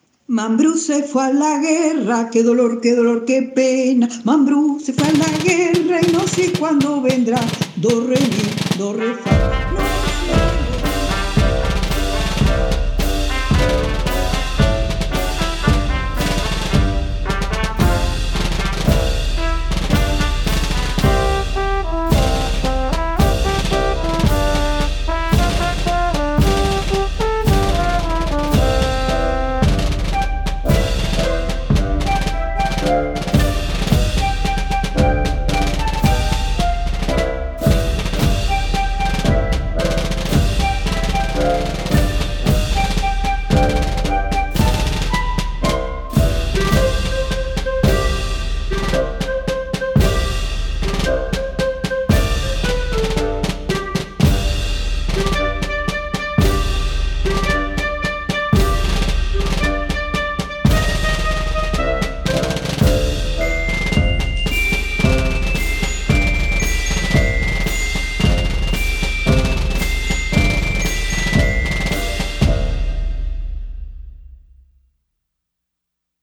solamente instrumentales
canciones tradicionales